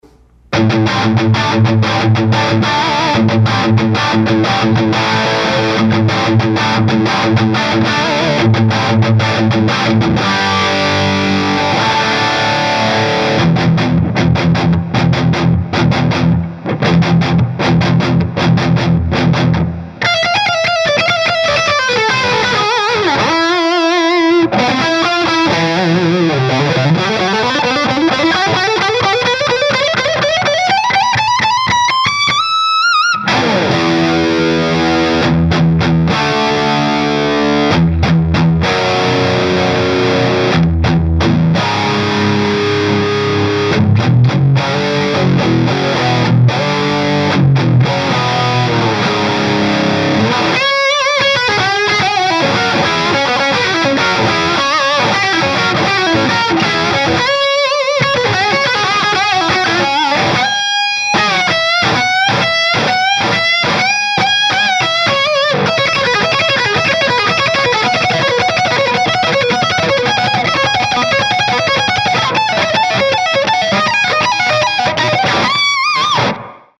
12″ – Guitarra – Inflames
Inflames é um falante com voice britânico inspirado em um dos mais renomados timbres dessa linhagem, apresenta harmônicos extremamente detalhados e complexos, graves encorpados e definidos, alcance médio rico e detalhado com características do timbre usado por Slash, Steve Stevens e Peter Frampton.
Inflames_drive2-1.mp3